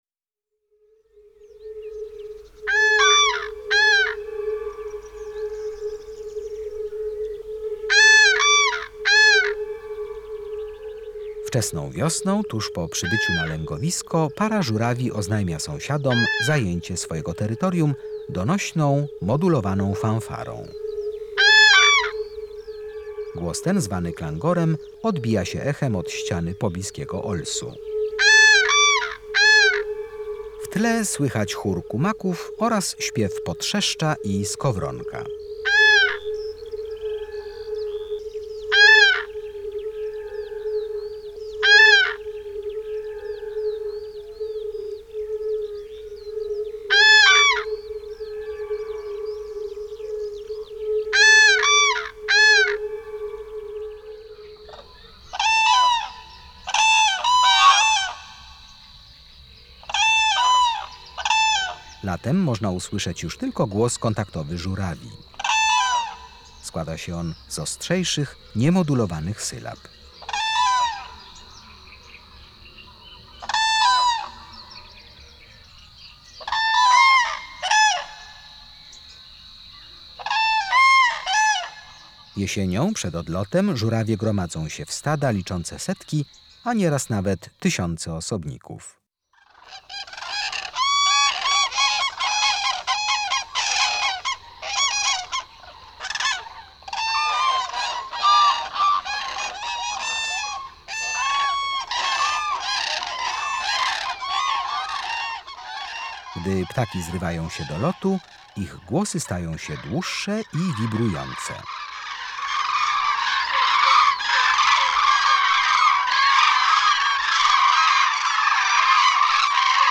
16-Zuraw.mp3